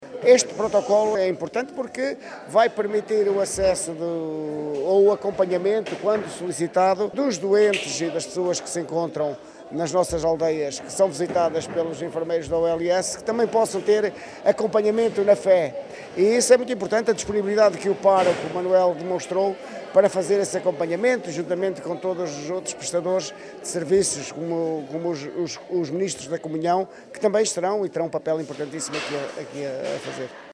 Também do lado do município, Carlos Barroso, Vice-presidente, vê neste protocolo uma mais-valia para o bem-estar dos munícipes.
Declarações à margem da assinatura do protocolo de colaboração entre a ULS Nordeste e a UPSA, inserida no Seminário “Saúde – Do cuidar ao curar: a Fé como acto curativo” que aconteceu na manhã de sábado na aldeia de Arcas, Macedo de Cavaleiros.